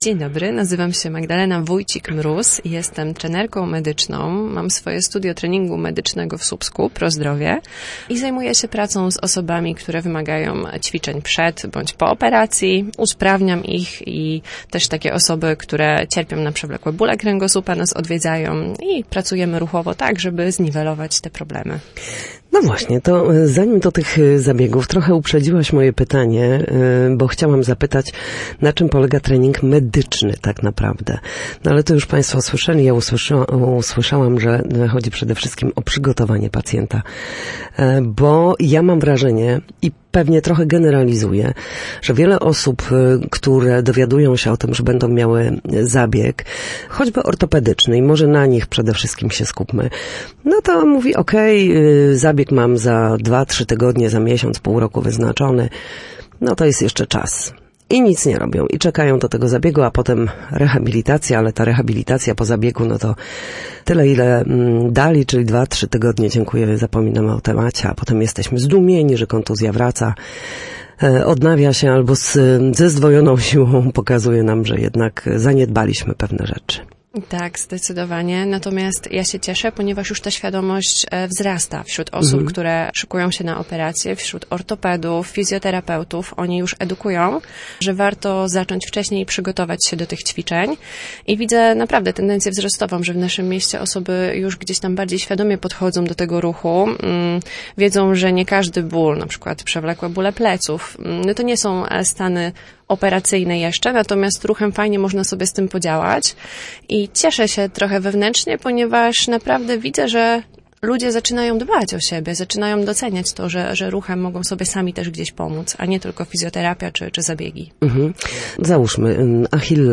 Gosc_Trener_medyczny.mp3